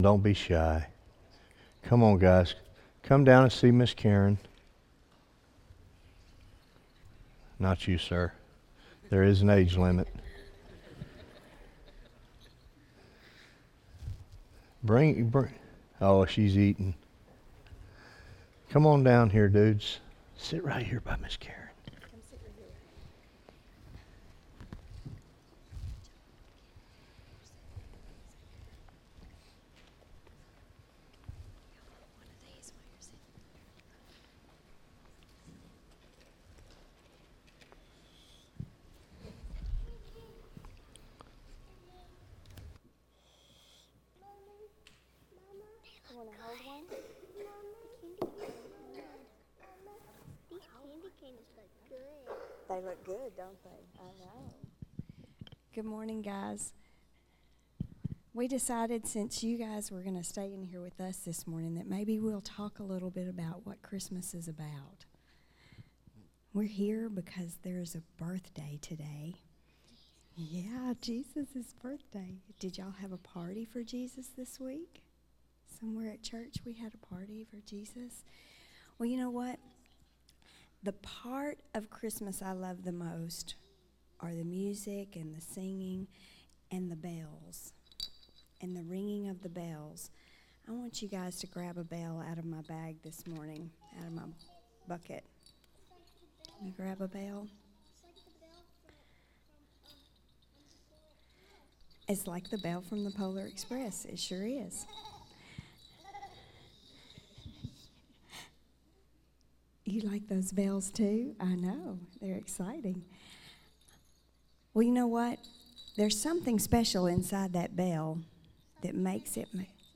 Morning Worship
by Office Manager | Dec 27, 2016 | Bulletin, Sermons | 0 comments